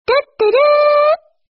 Nada notifikasi Tuthuruu…
Kategori: Nada dering
Keterangan: Salah satu suara notifikasi yang lucu dan lagi ngehits di TikTok adalah Tuthuruu...
nada-notifikasi-tuthuruu-id-www_tiengdong_com.mp3